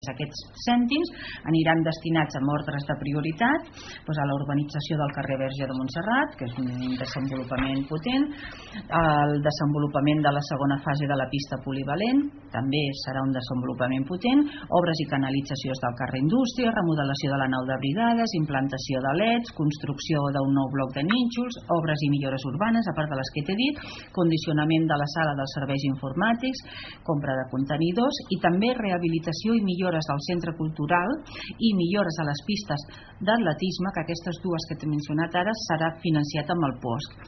La regidora d’hisenda de Malgrat, Isabel Ortiz ho detalla a RP.